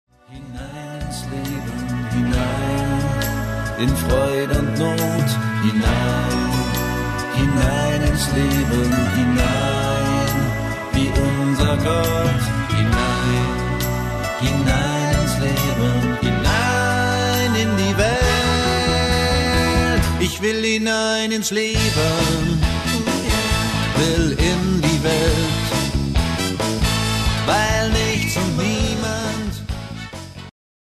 • moderne Popsongs